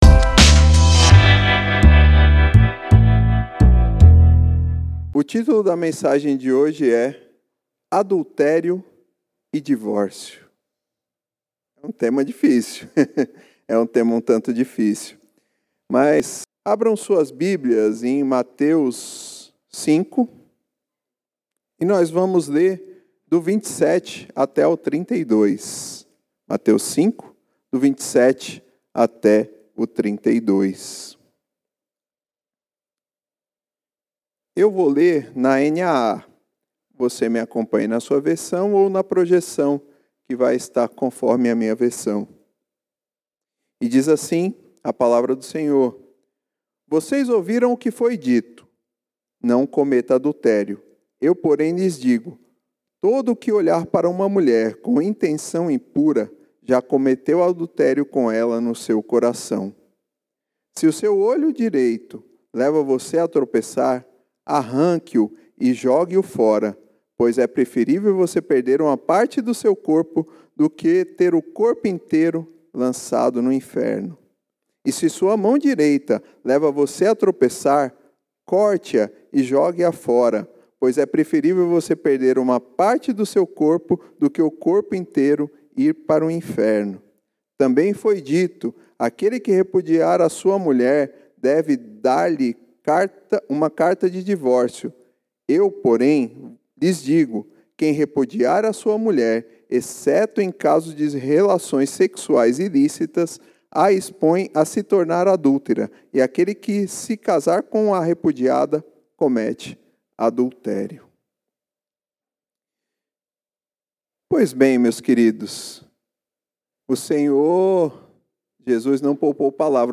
Mensagem realizada no Culto de Reflexão de Oração.